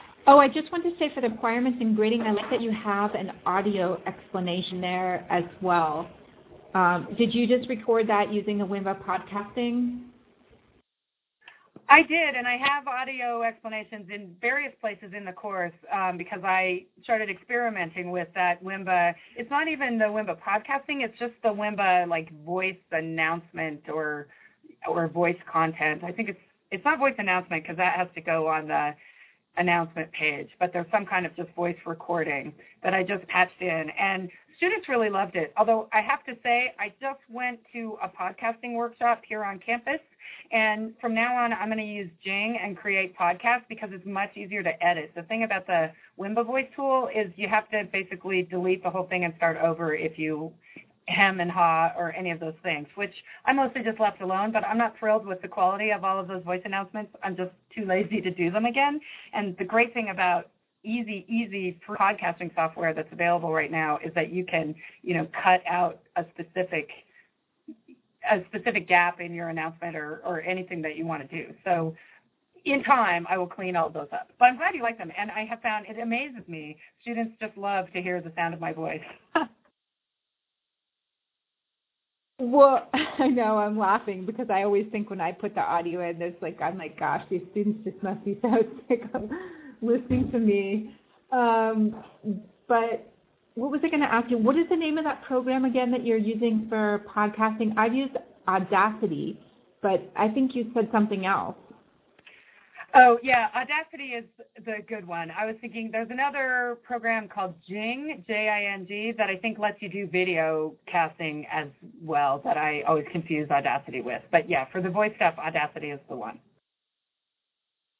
We did a lot of troubleshooting during our course review sessions, which were recorded.